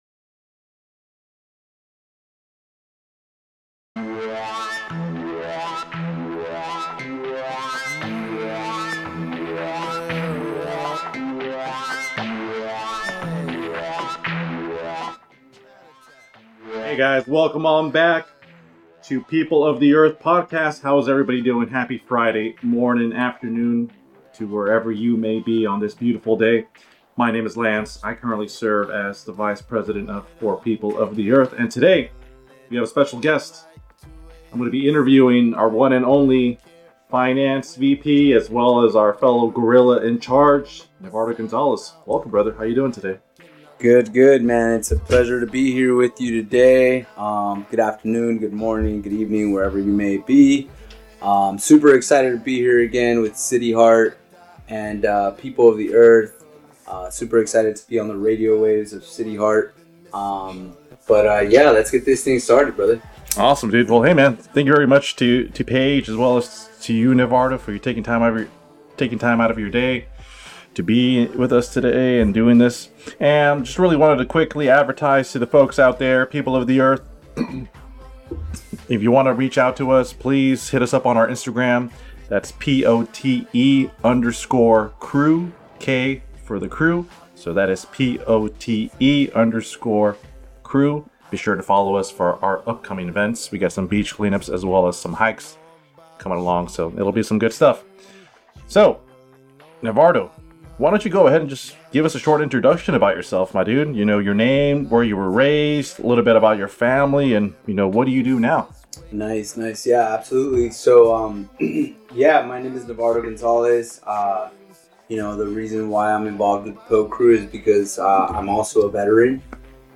This episode of POTE aired live on CityHeART Radio on Friday July 21 at 12pm